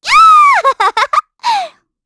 Estelle-Vox_Happy5_kr.wav